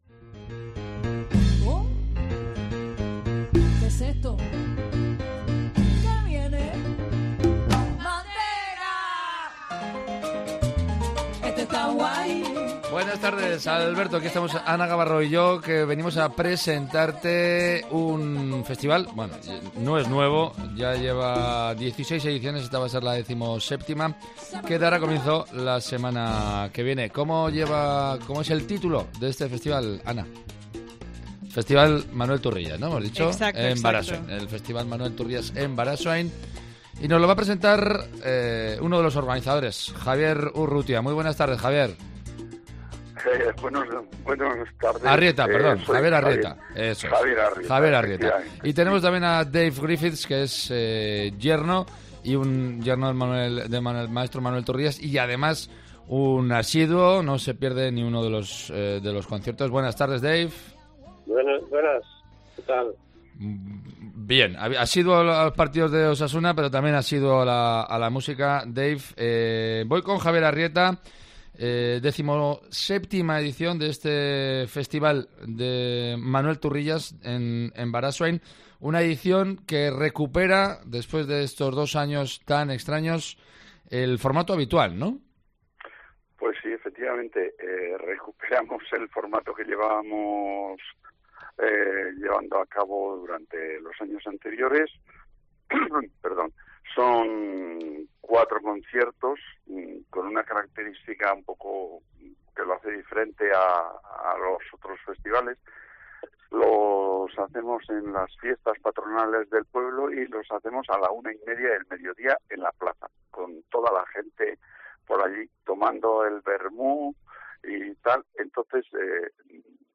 Entrevista sobre el Festival de Música Manuel Turrillas